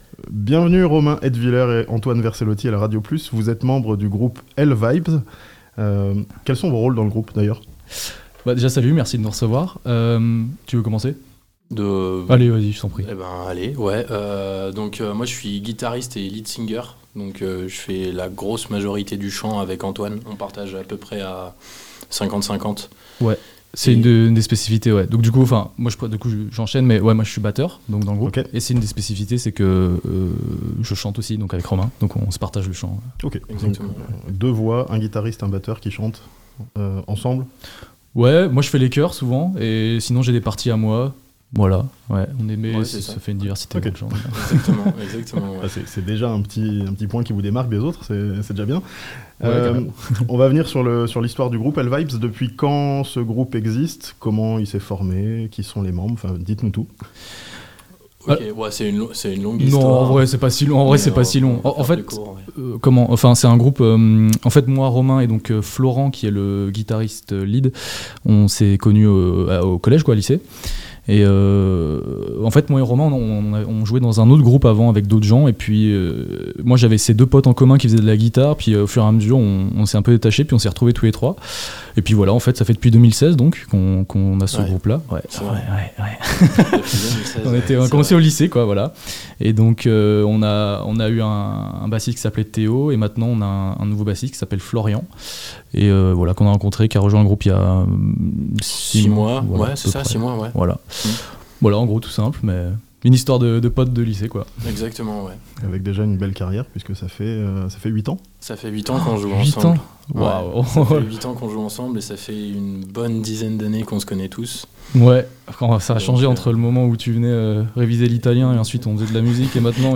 Un nouvel album pour Hellvibes, groupe de punk rock Chablaisien (interview)